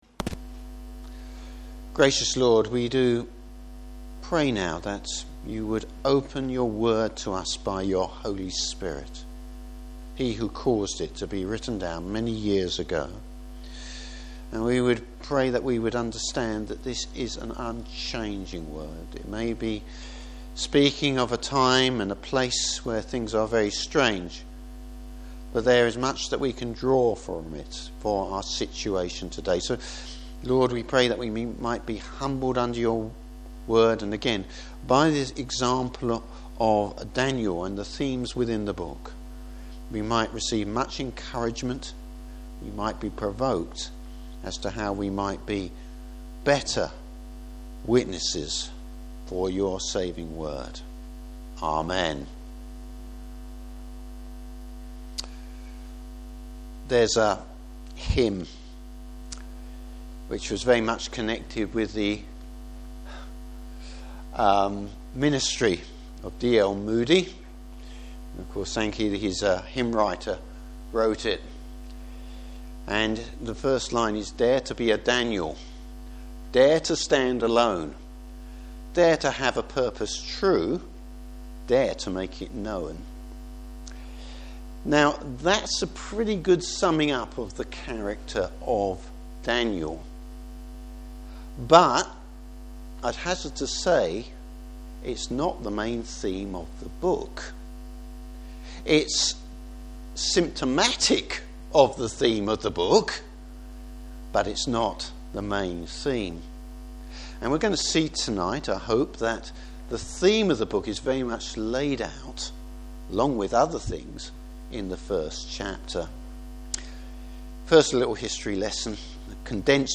Service Type: Evening Service Refusing to compromise with the world.